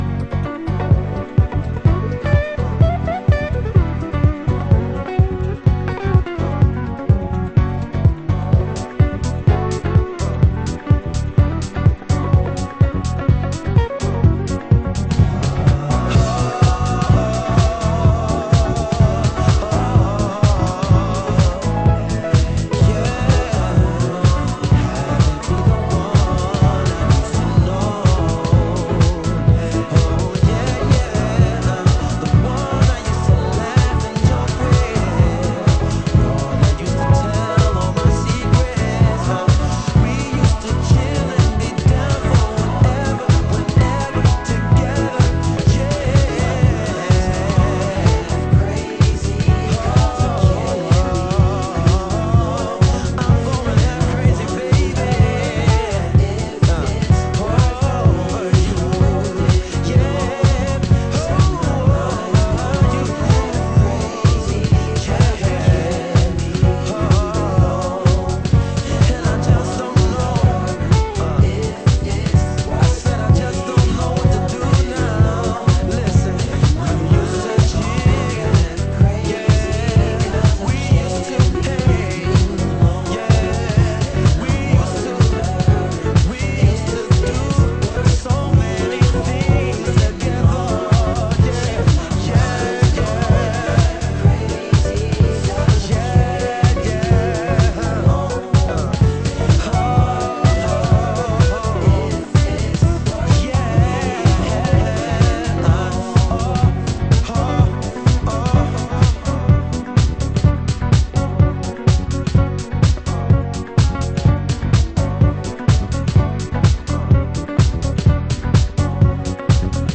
★DEEP HOUSE 歌 掘り出し
盤質：少しチリノイズ有